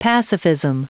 Prononciation du mot pacifism en anglais (fichier audio)
Prononciation du mot : pacifism